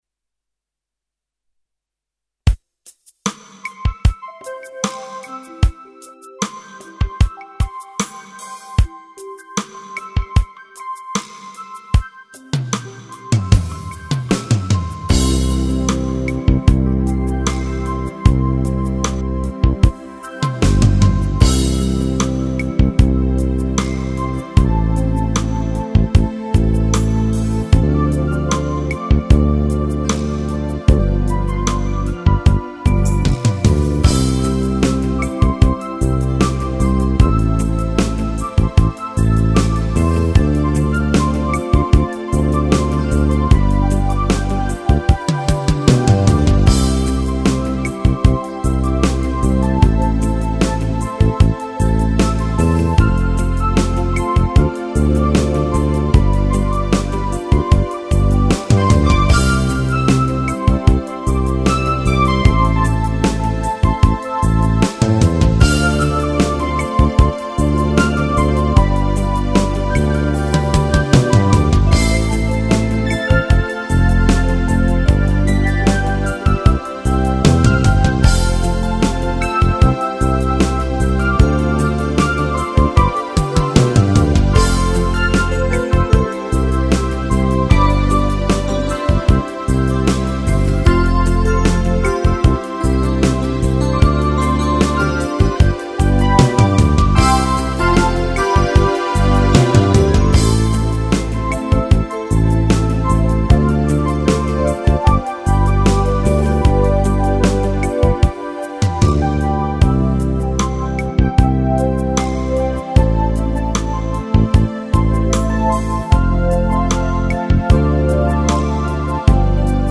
Скачать христианскую музыку и фонограммы.